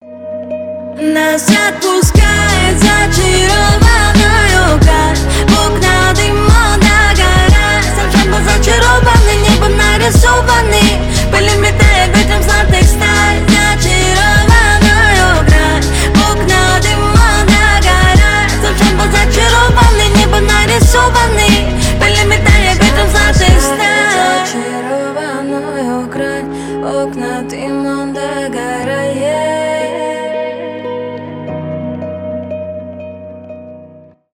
дуэт , рэп